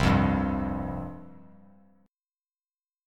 Csus4 chord